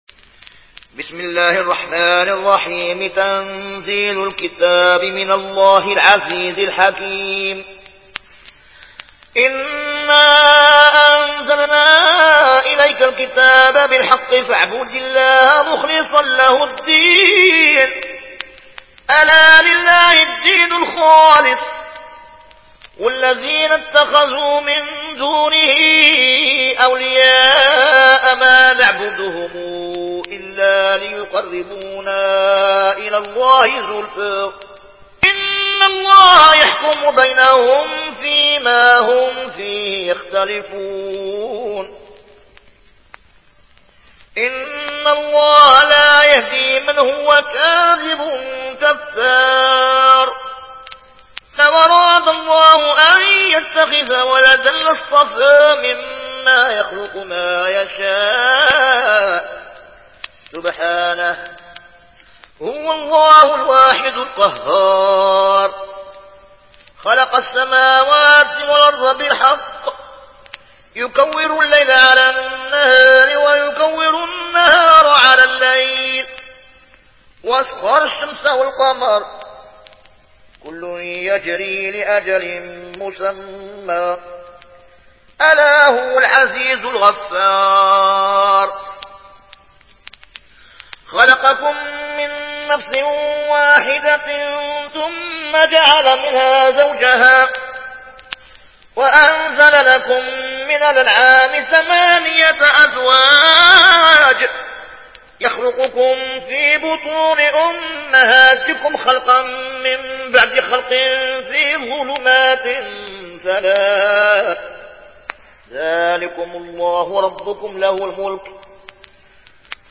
Warsh থেকে Nafi